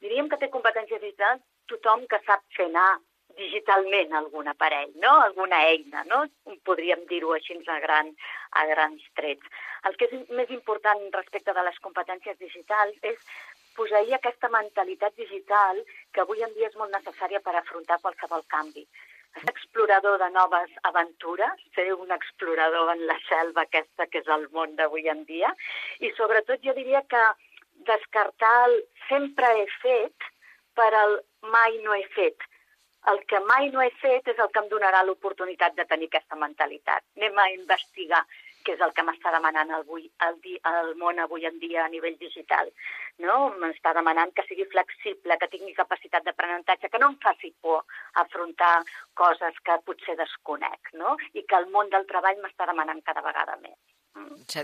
Aquest tall s’ha extret de l’espai “Enfoca’t” de Ràdio Mollet del 21 de març en el qual col·laborem cada setmana.
radio-competencies-digitals.mp3